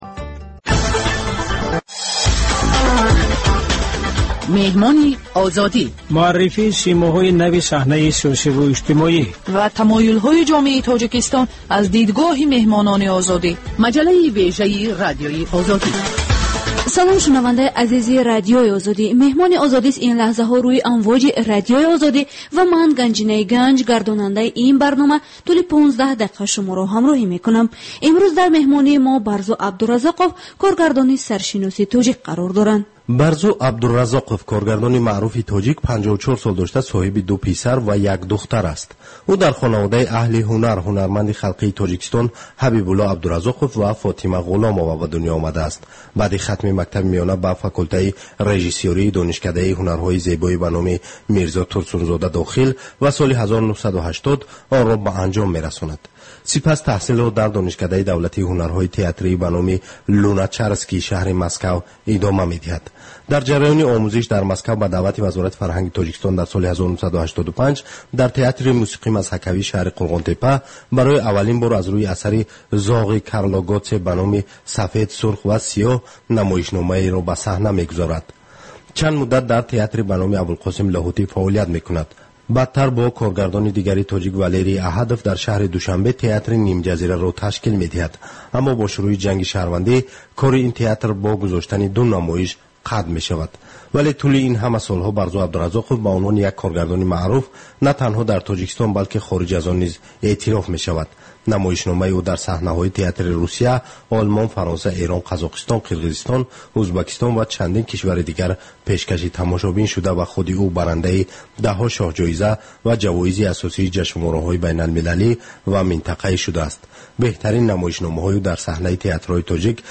Гуфтугӯи ошкоро бо шахсони саршинос ва мӯътабари Тоҷикистон.